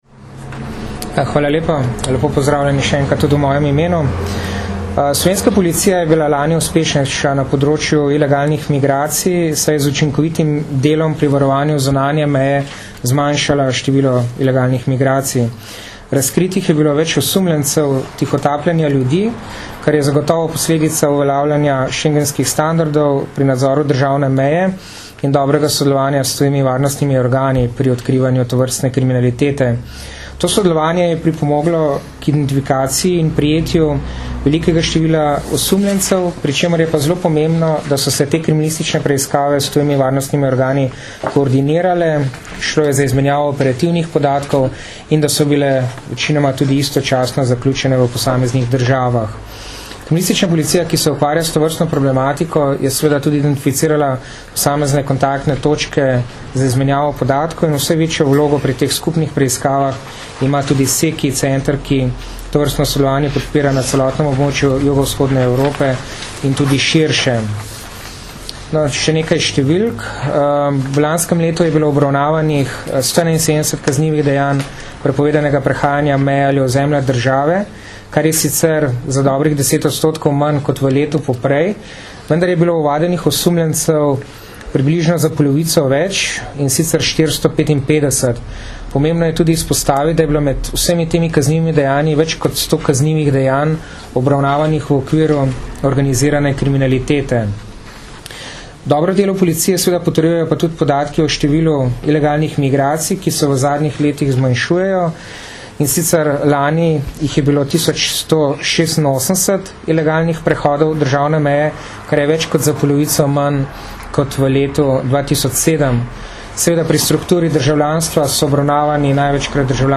Uspešno zaključena večmesečna preiskava kaznivih dejanj prepovedanega prehajanja meje - informacija z novinarske konference
Zvočni posnetek izjave